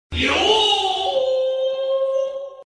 В подборке — культовые фразы, музыкальные моменты и эффекты длиной до 19 секунд.